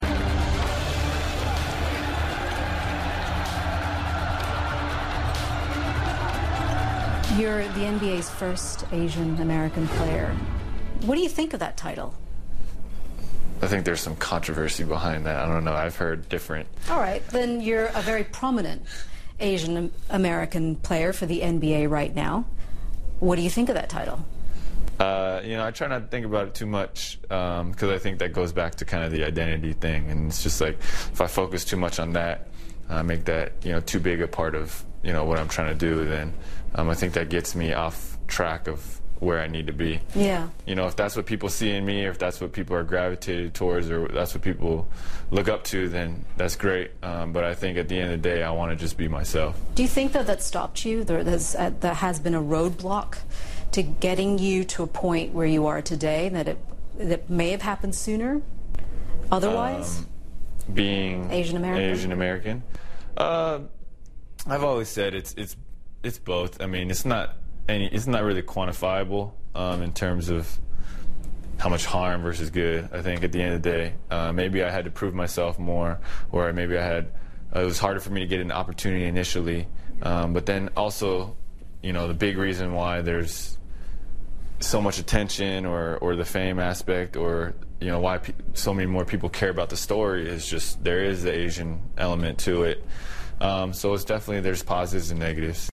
访谈录 2013-11-01&11-03 NBA球星林书豪：我不会改变球风 听力文件下载—在线英语听力室